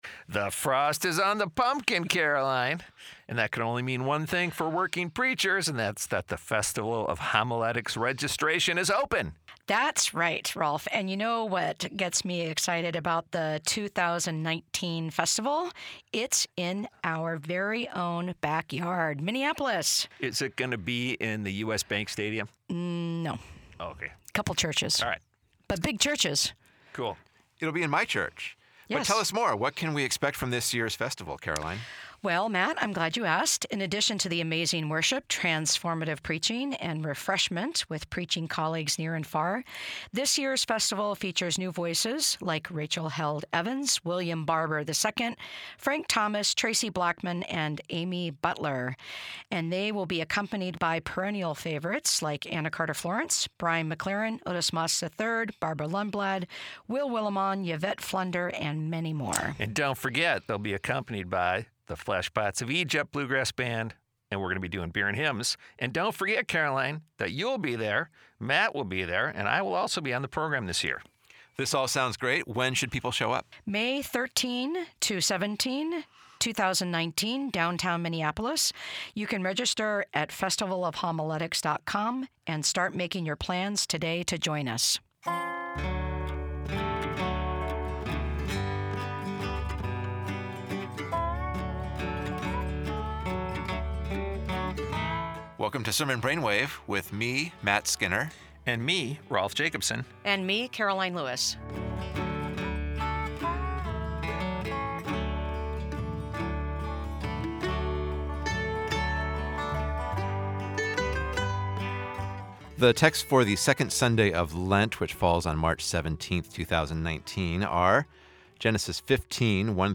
Discussion on texts for Second Sunday in Lent (March 17, 2019): (Gospel) Luke 13:31-35; (First Reading) Genesis 15:1-12, 17-18; Psalm 27; (Second Reading) Philippians 3:17--4:1. Recorded at Luther Seminary, Saint Paul, Minn., for Working Preacher. read more...